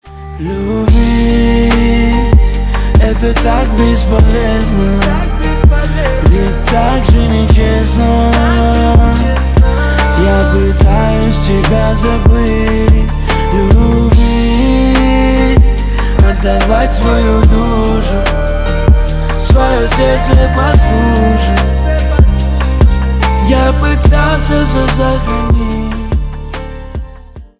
Поп Музыка
спокойные
грустные